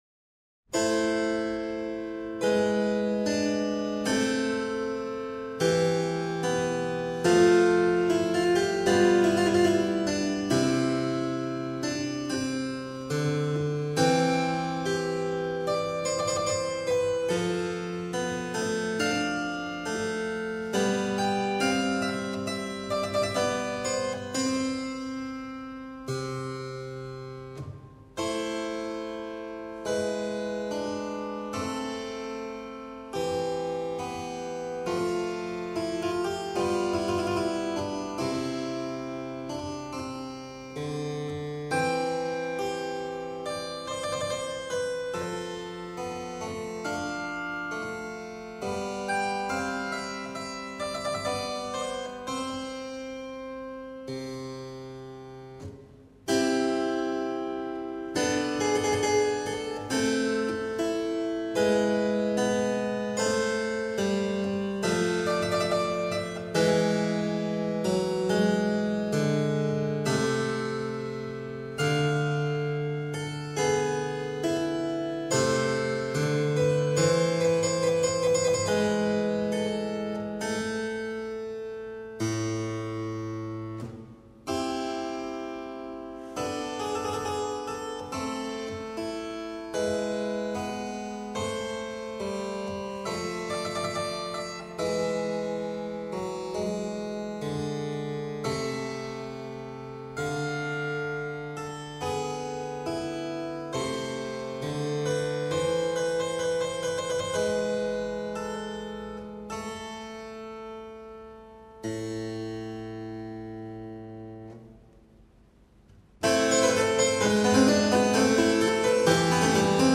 Six sets of Air & Variations ("Partitas") - Harpsichord